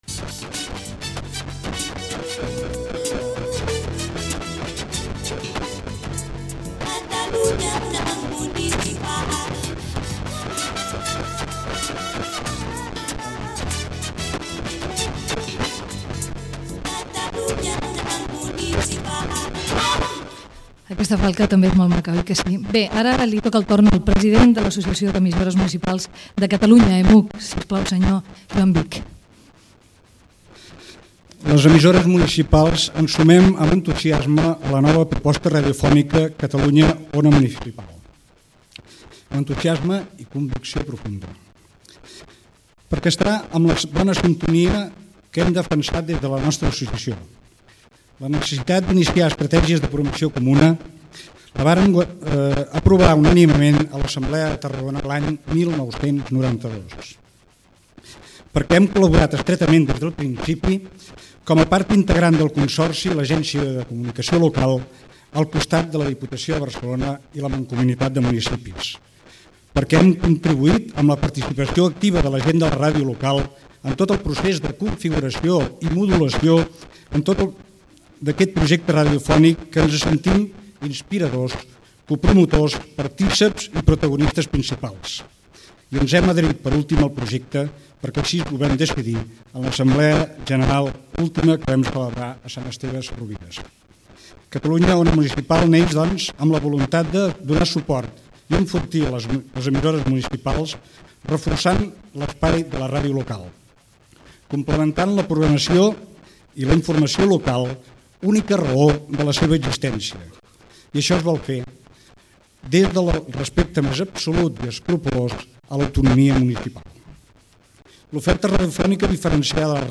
Acte de presentació del projecte COM Ràdio.
Informatiu
Àudio defectuós.